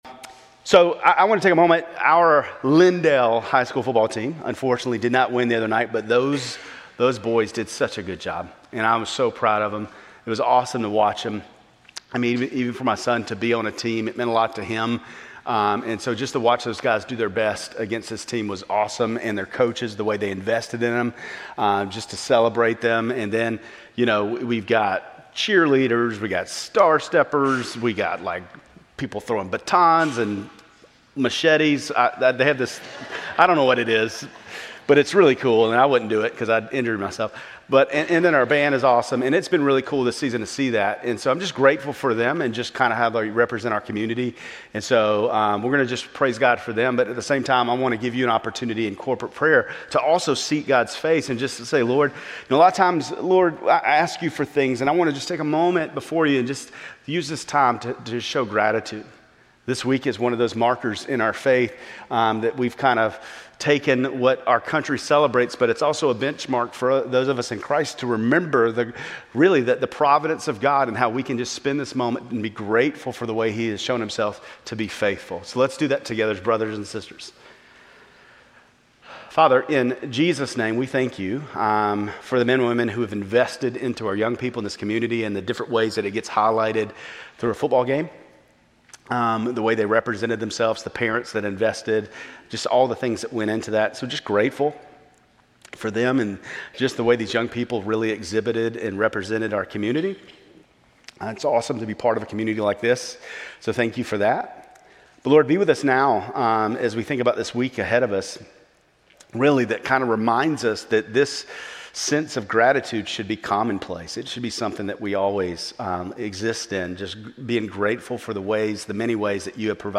Grace Community Church Lindale Campus Sermons 11_23 Lindale Campus Nov 24 2025 | 00:31:50 1x 00:00 / 00:31:50 Subscribe Share RSS Feed Share Link Embed